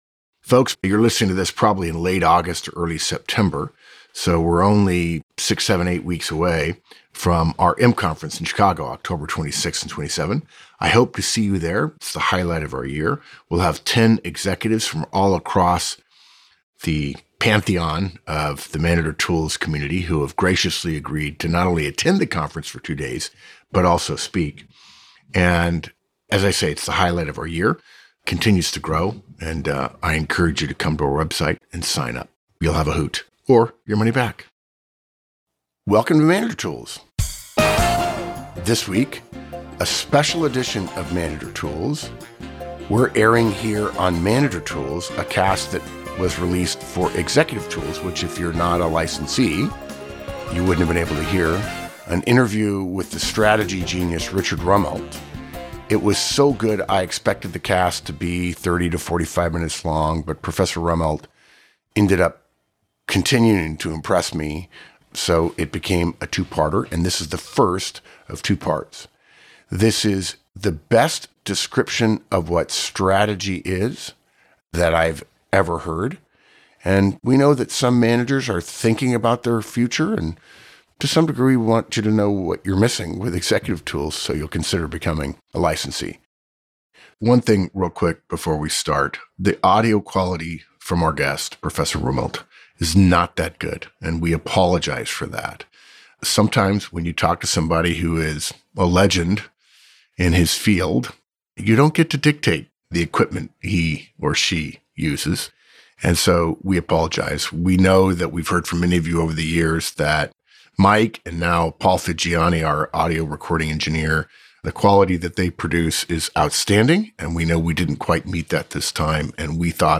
Part 1 of our interview with Richard Rumelt, strategy professor at UCLA Anderson and author of Good Strategy/Bad Strategy.